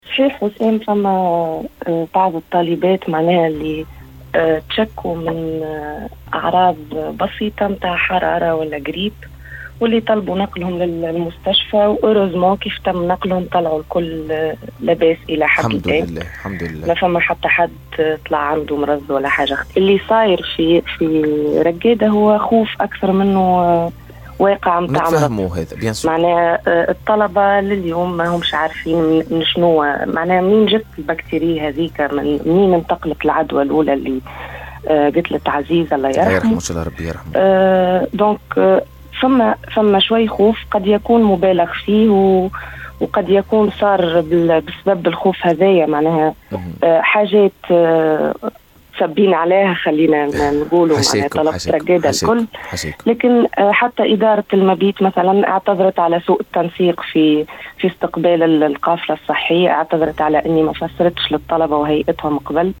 مداخلة في برنامج الدوسي على الحياة أف أم